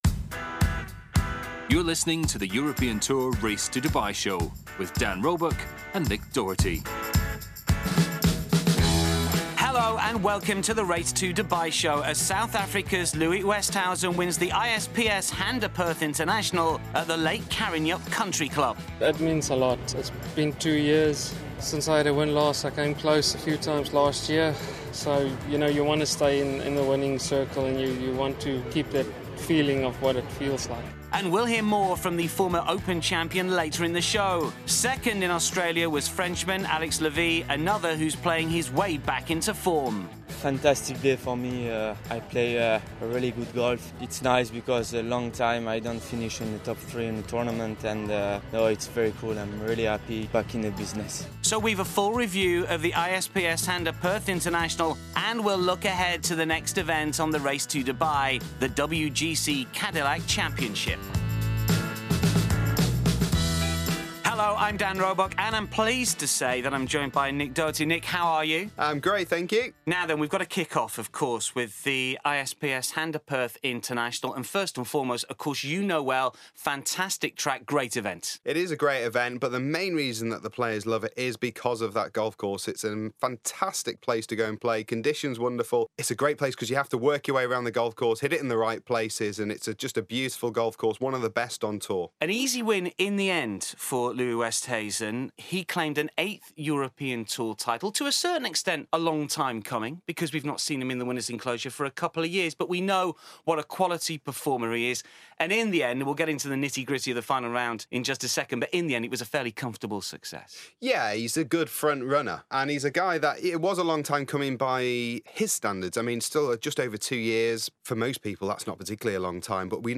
Former Open champion Louis Oosthuizen talks about his victory at the ISPS HANDA Perth International.